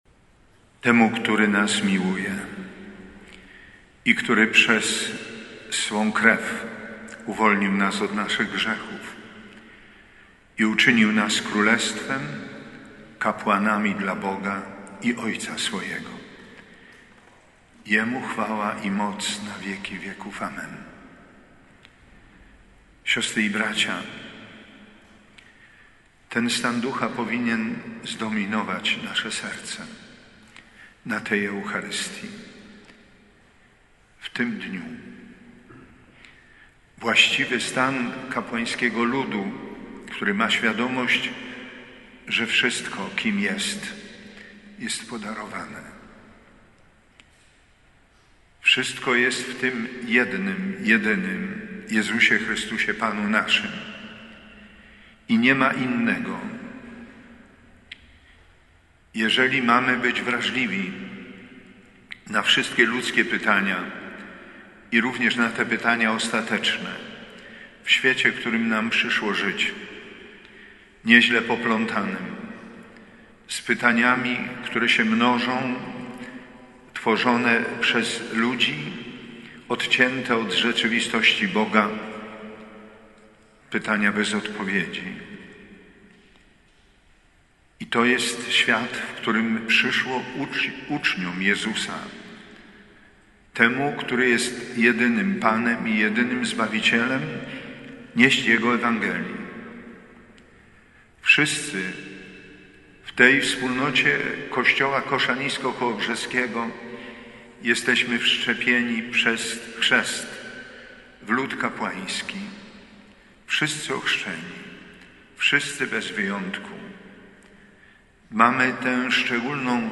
Bp Edward Dajczak 26 września konsekrował Krzyżmo oraz pobłogosławił oleje chorych i katechumenów.
Bp Dajczak przypomniał w homilii, że każde czasy mają swoje wyzwania, ale także i potrzebę głoszenia Ewangelii.